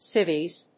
/síviz(米国英語)/